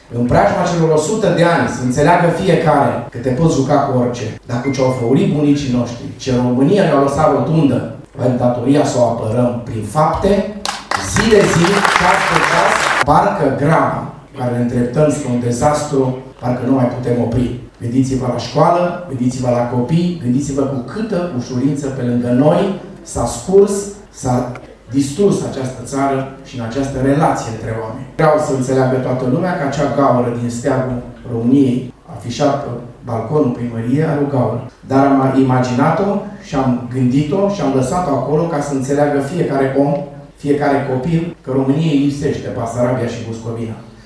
Primarul Dorin Florea le-a transmis târgumureșenilor să se lupte pentru apărarea valorilor țării și să prețuiască sacrificiul înaintașilor: